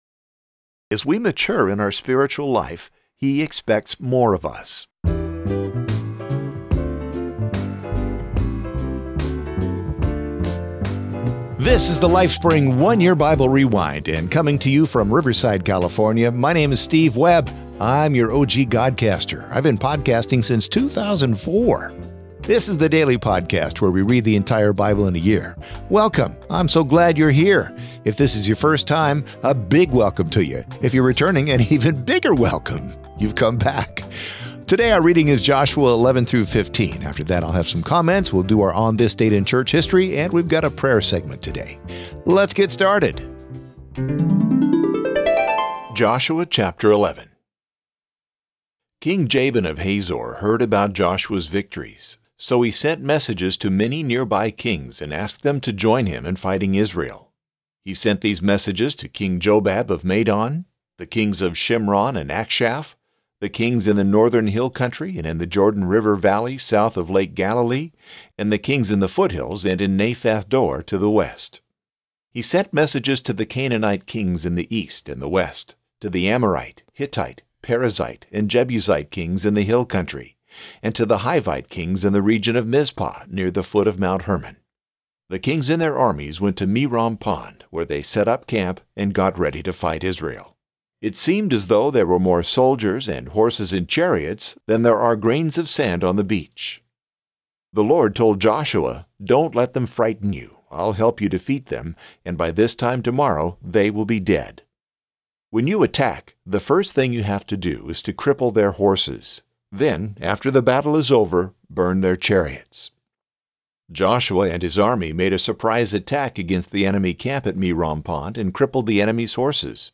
Translation notes: Joshua 11 is read from the CEV . Joshua 12–15 are read from the NET .